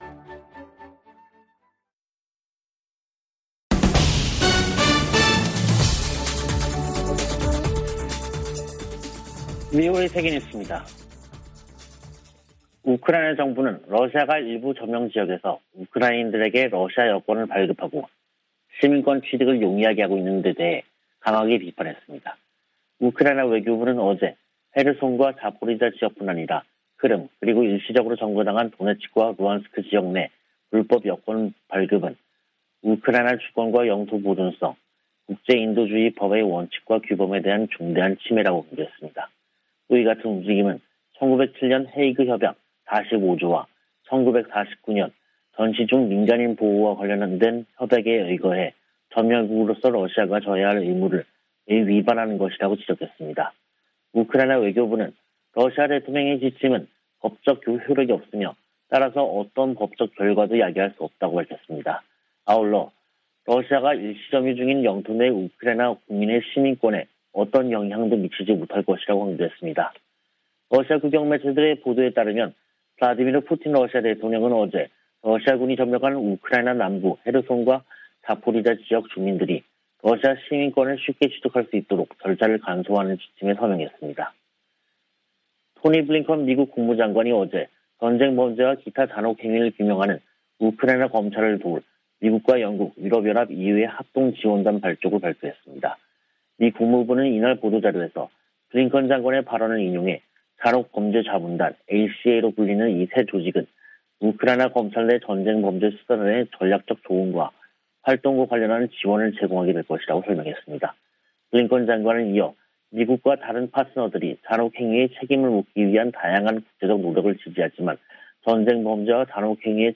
VOA 한국어 간판 뉴스 프로그램 '뉴스 투데이', 2022년 5월 26일 3부 방송입니다. 미 국무부 고위 관리가 북한의 탄도미사일 발사를 강력히 규탄하면서도 인도적 지원을 여전히 지지한다는 입장을 밝혔습니다. 유엔은 북한의 탄도미사일 발사가 긴장만 고조시킨다며 완전한 비핵화를 위한 외교적 관여를 촉구했습니다. 유엔 안보리가 새 대북 결의안을 표결에 부칩니다.